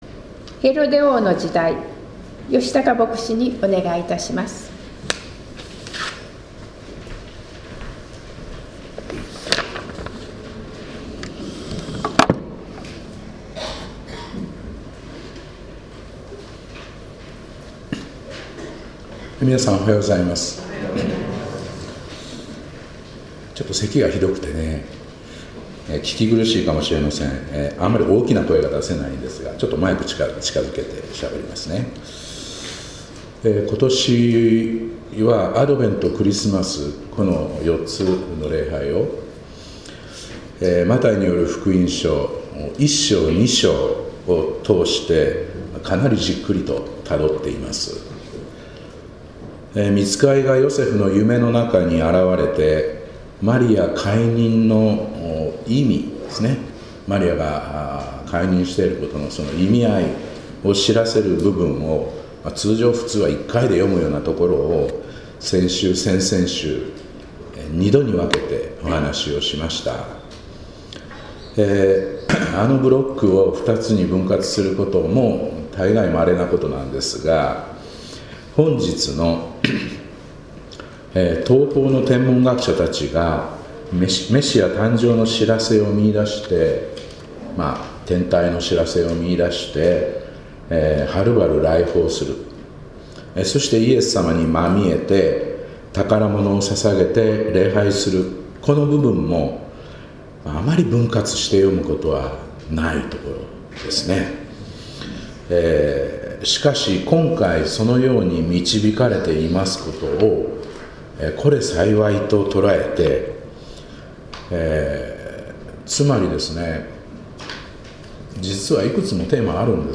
2024年12月15日礼拝「ヘロデ王の時代に」